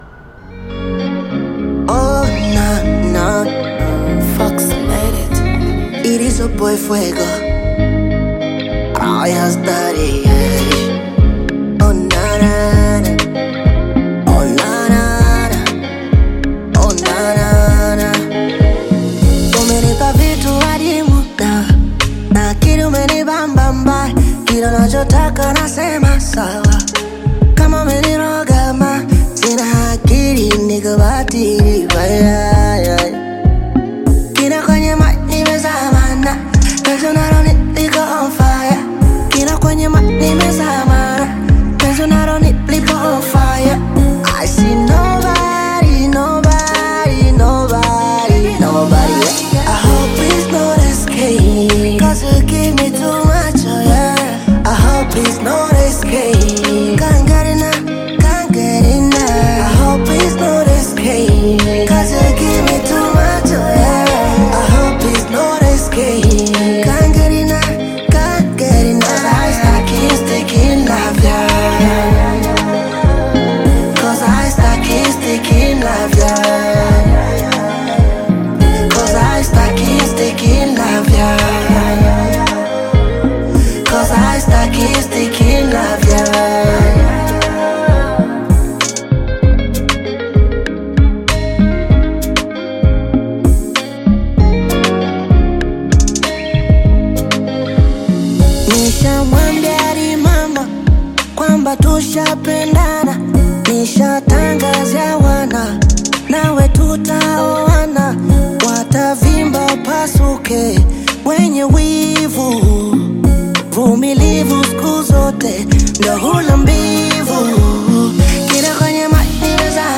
Tanzanian Bongo Flava artist, singer and songwriter
Bongo Flava You may also like